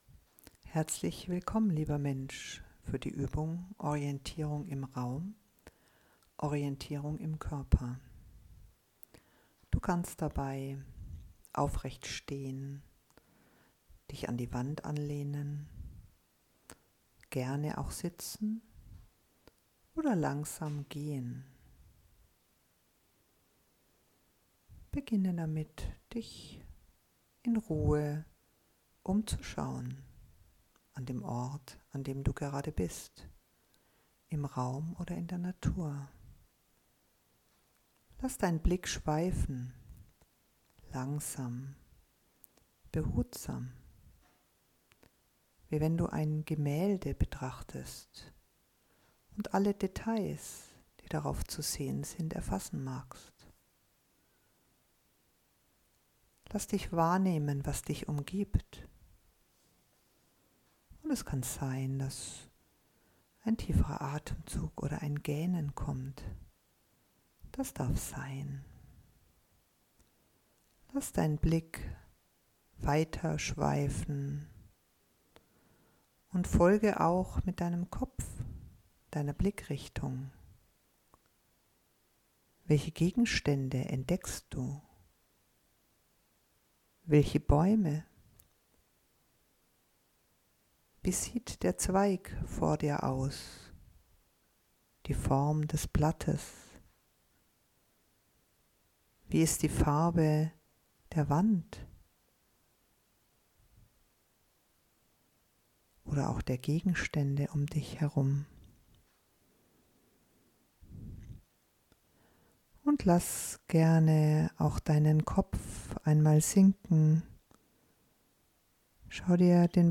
Es gibt eine kurze Pause zwischen der Orientierung im Raum und der Orientierung im Körper, so dass du selbst wählen kannst, ob du beide Teile oder nur den ersten Teil nutzt.